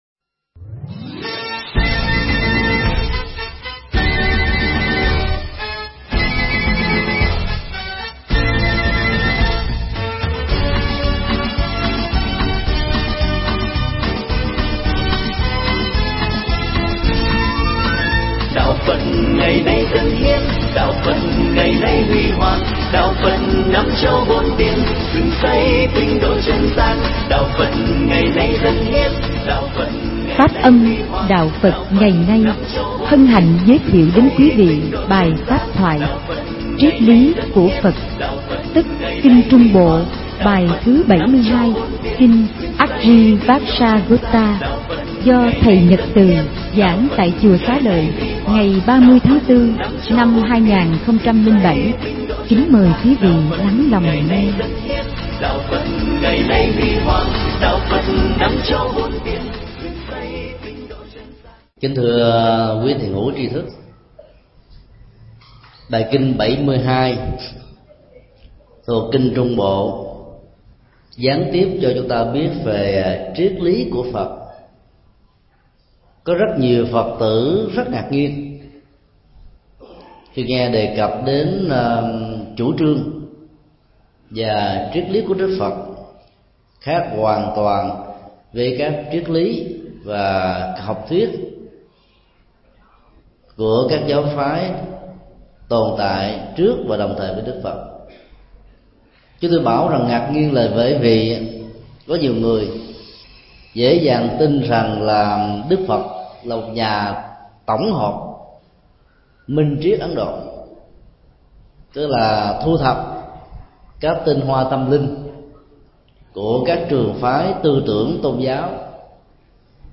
Mp3 Pháp Thoại Kinh Trung Bộ 72 (Kinh Aggivacchagotta) – Triết lý của Phật – Thượng Tọa Thích Nhật Từ Giảng tại chùa Xá Lợi, ngày 29 tháng 4 năm 2007